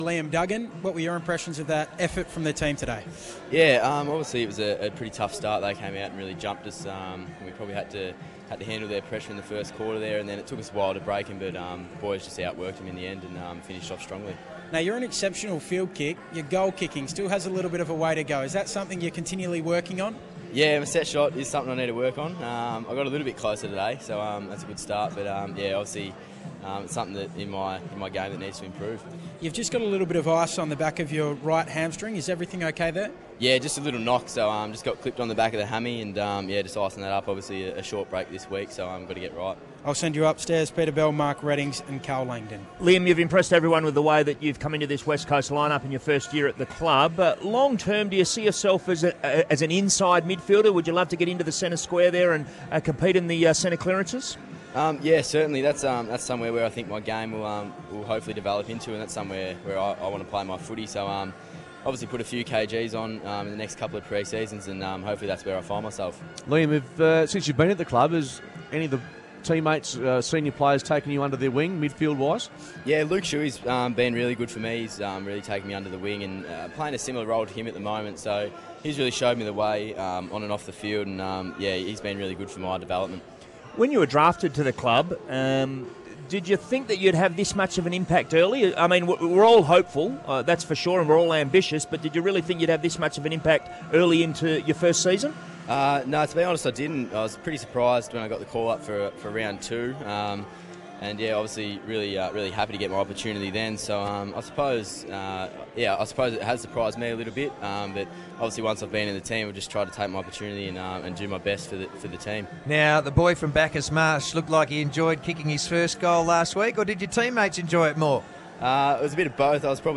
Liam Duggan post match interview